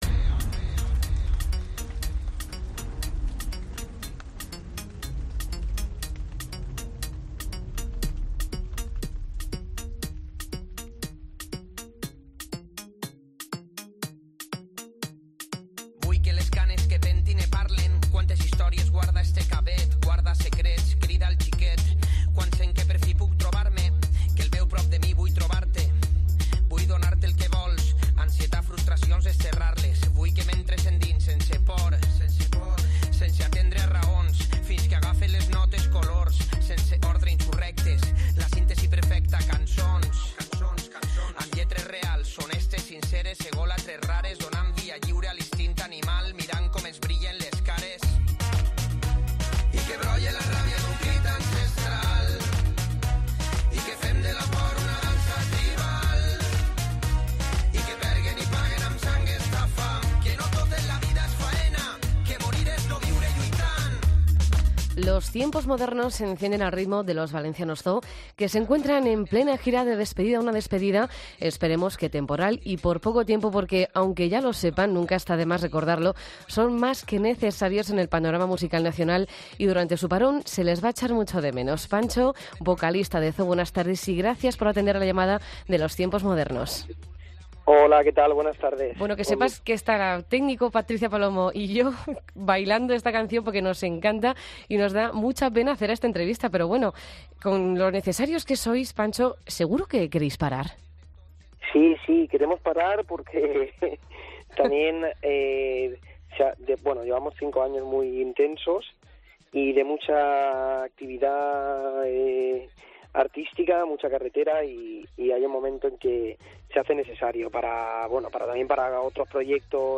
Entrevista a ZOO en los Tiempos Modernos